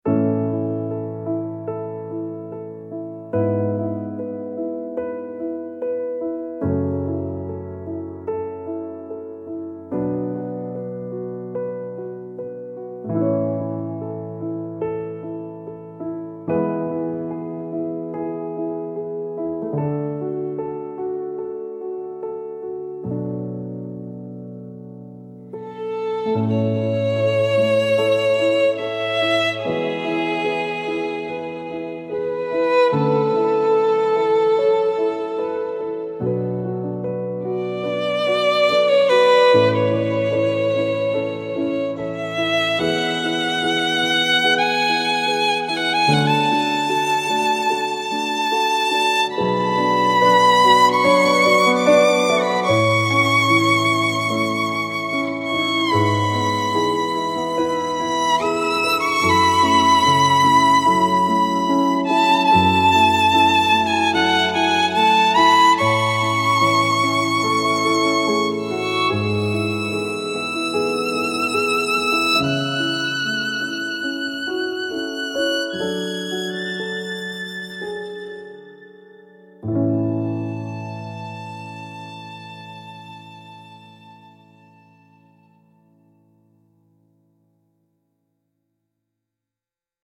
bittersweet solo violin melody over gentle piano chords, reflective and moving